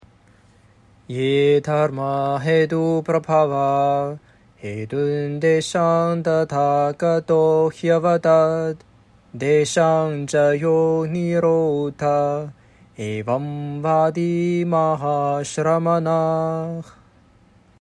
这一颂的梵文，是生韵（jāti）类的圣母韵（āryā）：
一般来说，符合12·18·12·15个mātra的排列的就属于圣母韵（āryā），其中一个轻音节是1个mātra，一个重音节是2个mātra。